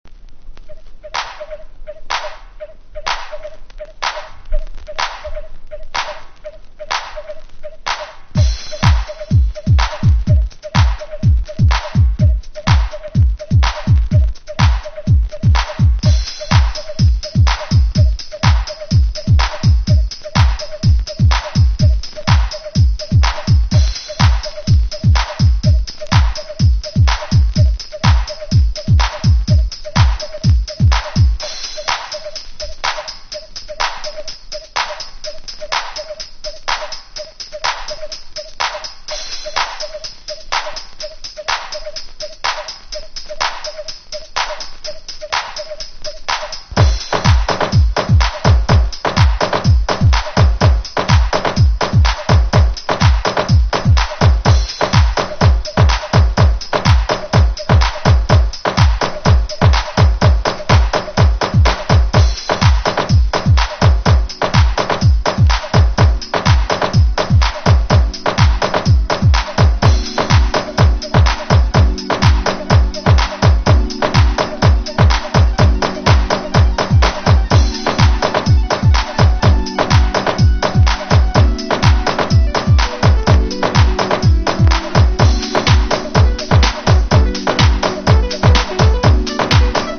DEEP HOUSE / EARLY HOUSE